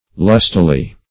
Lustily \Lus"ti*ly\, adv.